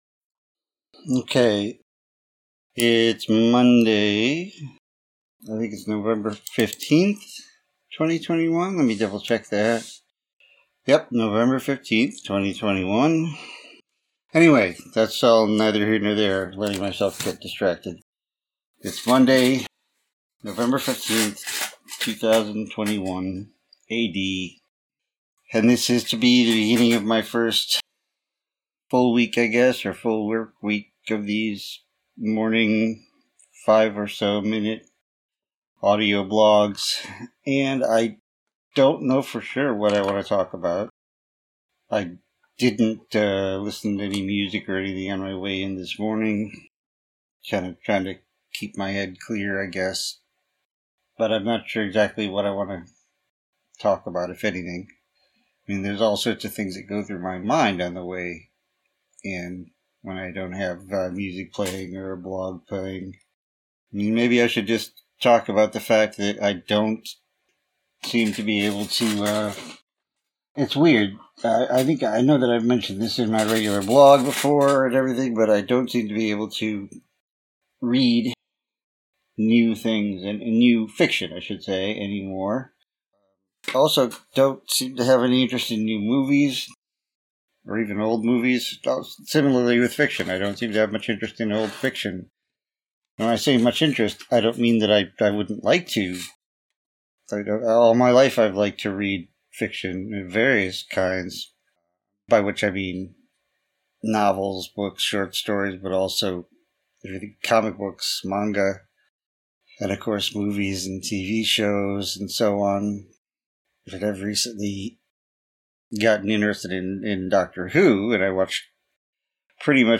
This is a combined audio blog of my near-daily recordings through the course of this week, starting Monday, skipping Thursday, and finishing today. I meander around various topics, some more coherent than others, including the pitfalls of social media compared to the legendary beginning of the internet and how it amplifies the already impressive degree of human stupidity. I talk a lot about chronic pain (physical and psychological) because I have been having a pretty nasty exacerbation of both this week.